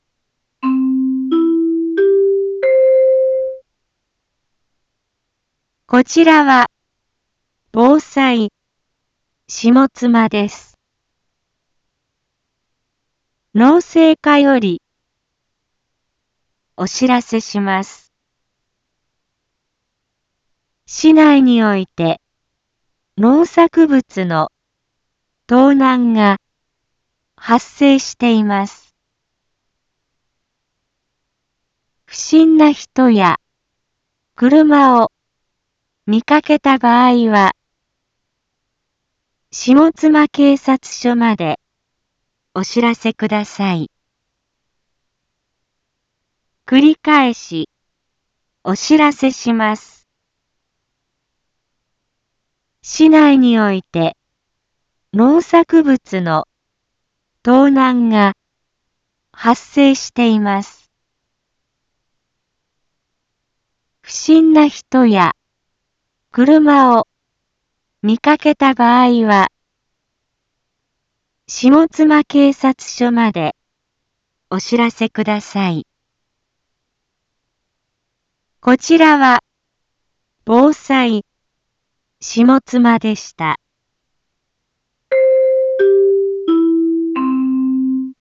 一般放送情報
Back Home 一般放送情報 音声放送 再生 一般放送情報 登録日時：2021-10-09 12:31:32 タイトル：農作物の盗難被害について インフォメーション：こちらは防災下妻です。